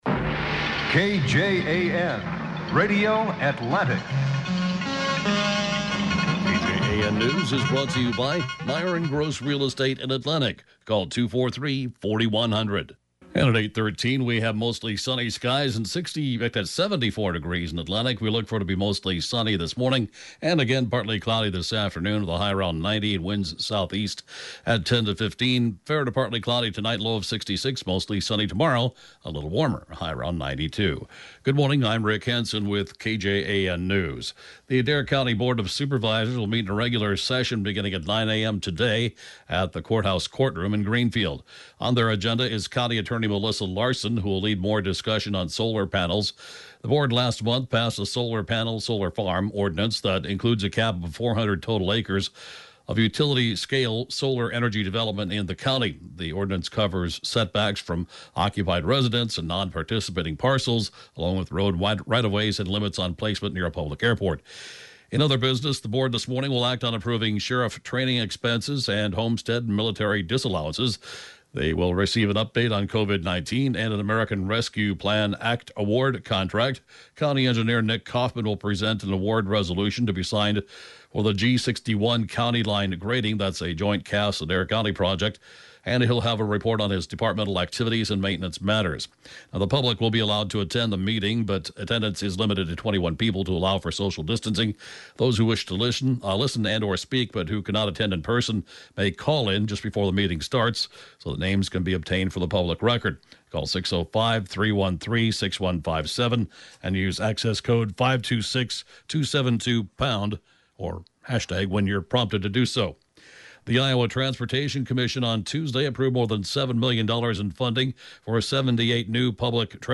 News, Podcasts